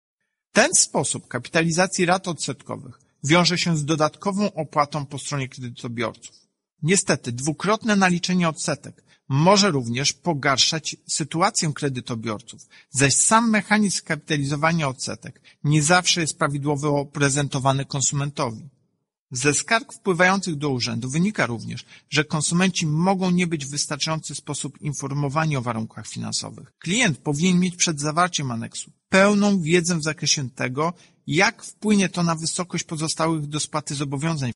Wakacje kredytowe nie są darmowym produktem, ale konsumenci powinni być dokładnie informowani o ofercie swojego banku – mówi przez UOKiK Tomasz Chróstny: